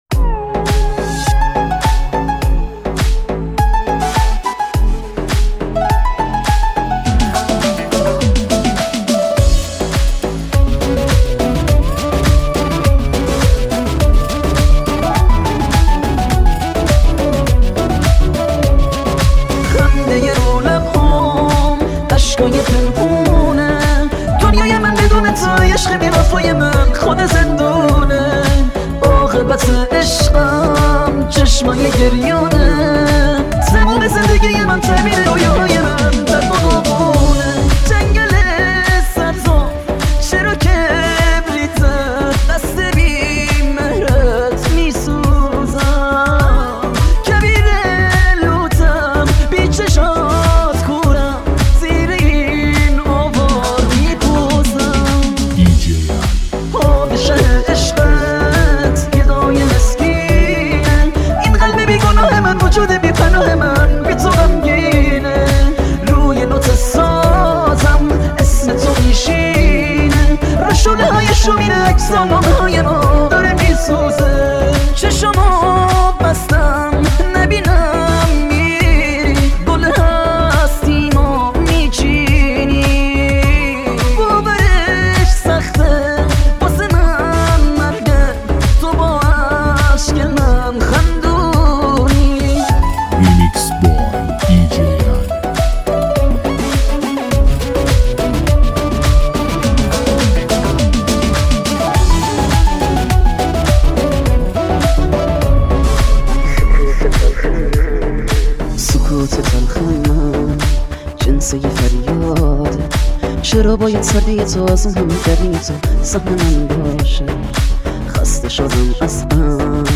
موسیقی پرانرژی و شنیدنی برای لحظاتی پر از احساس و شادی.